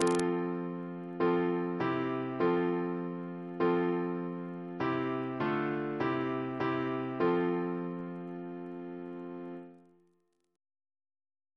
Single chant in F Composer: William Boyce (1710-1779), Organist and Composer to the Chapel Royal Reference psalters: H1940: 603